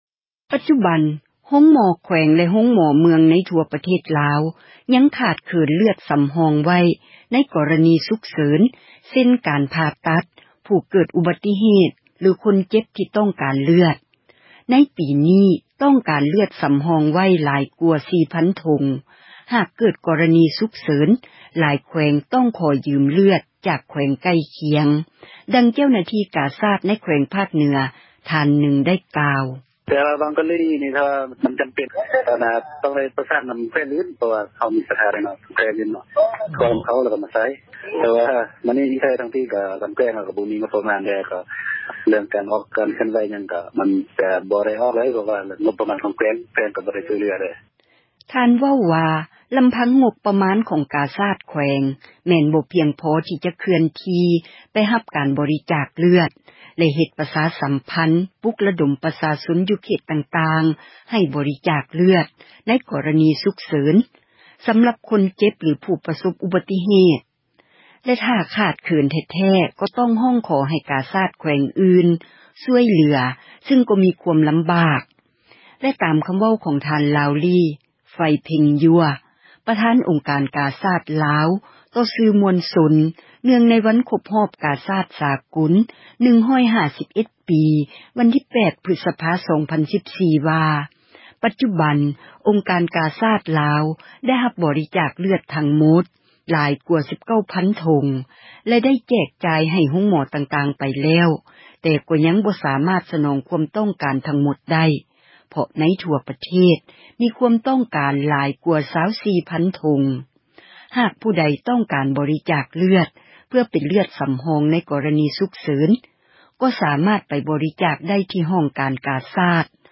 ດັ່ງ ເຈົ້າໜ້າທີ່ ກາຊາດ ໃນ ແຂວງ ພາກເໜືອ ທ່ານນຶ່ງ ກ່າວວ່າ: